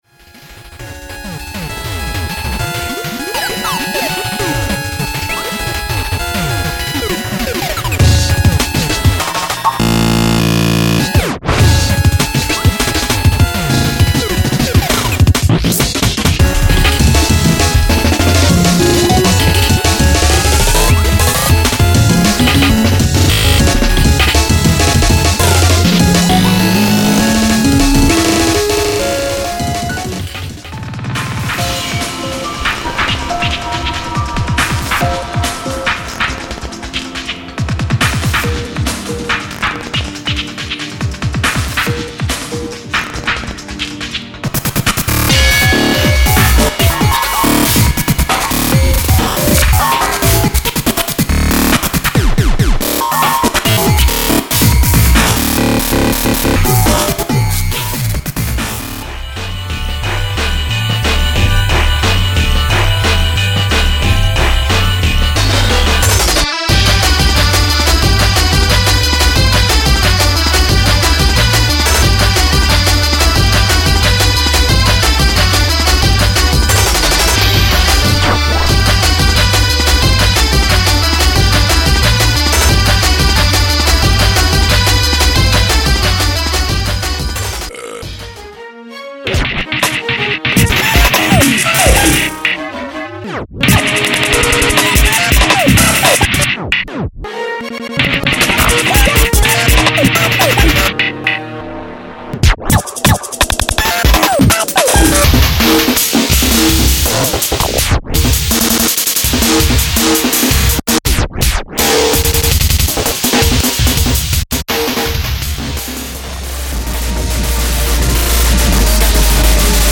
10/29　クロスフェードデモ公開